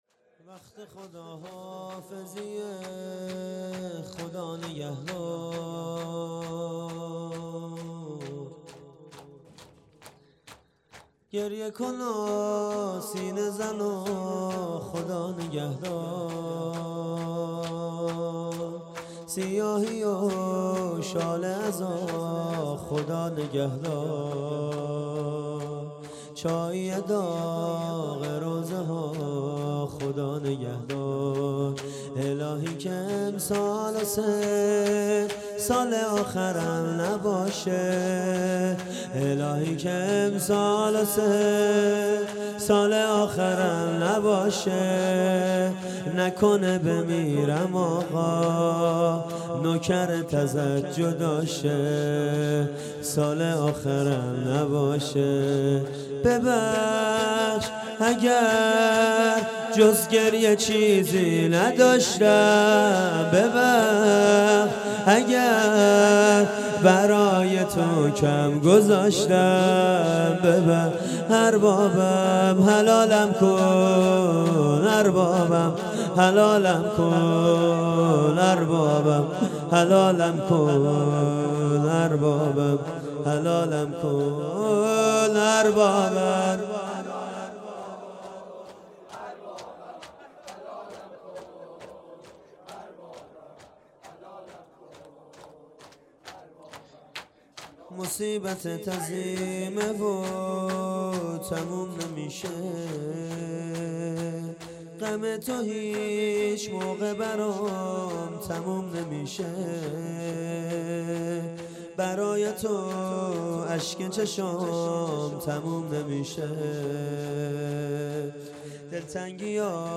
اقامه عزای شهادت امام رضا علیه السلام